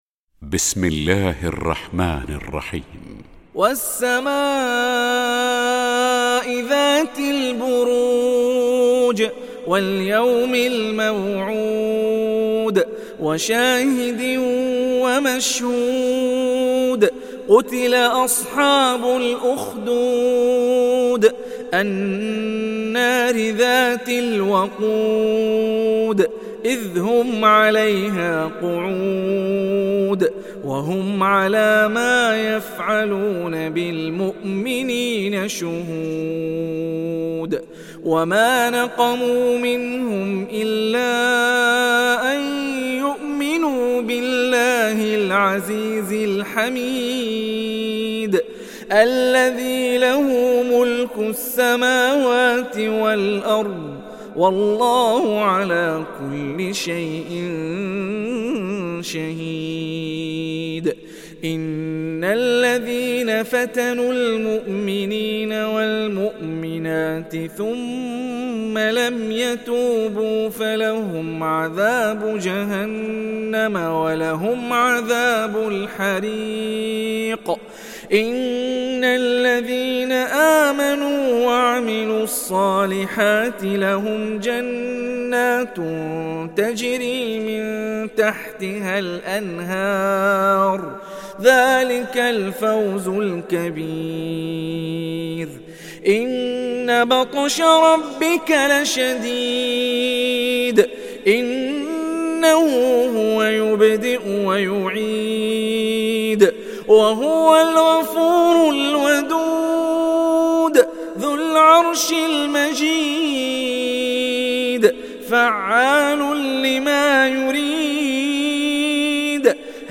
Surat Al Buruj Download mp3 Hani Rifai Riwayat Hafs dari Asim, Download Quran dan mendengarkan mp3 tautan langsung penuh